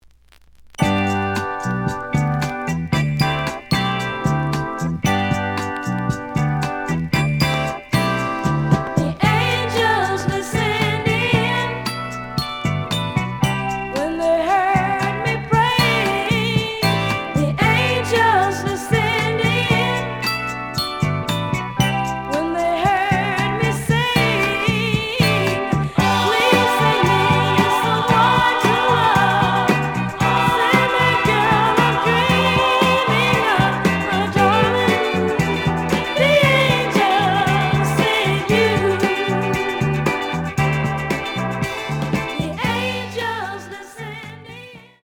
The audio sample is recorded from the actual item.
●Genre: Soul, 70's Soul
Some click noise on A side due to scratches.